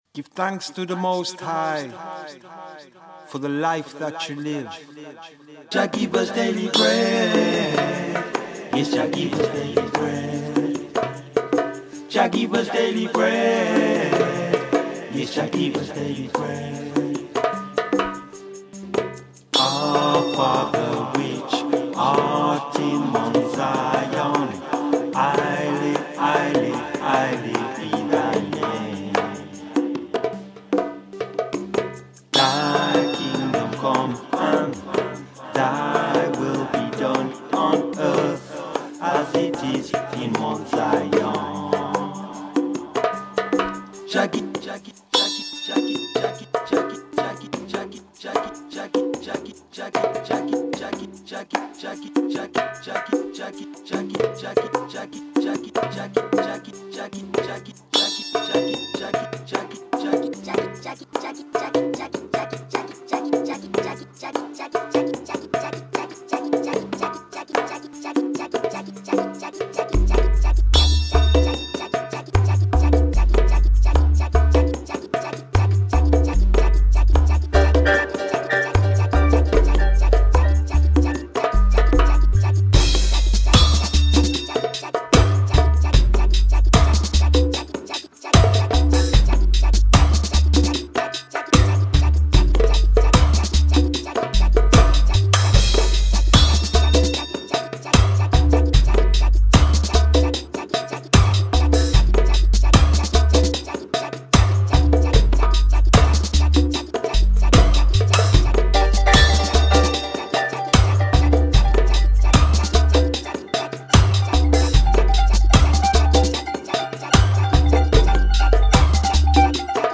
Dub version